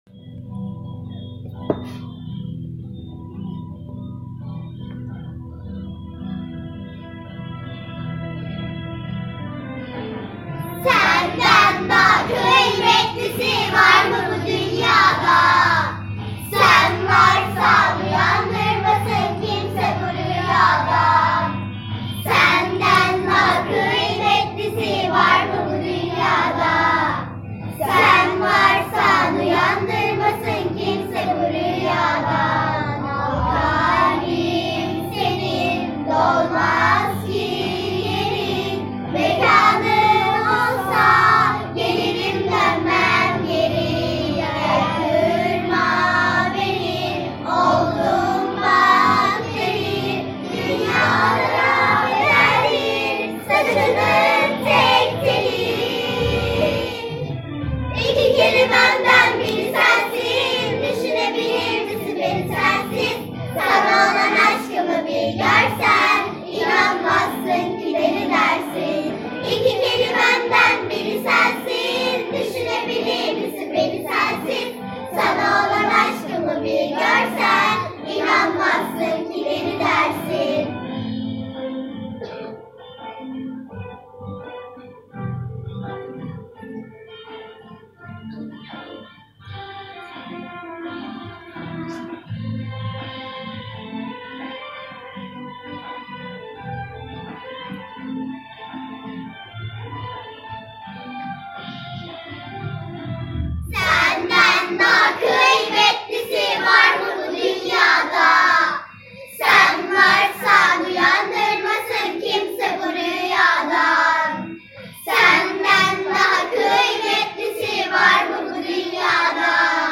Giho Şarkı Yarışması Albümü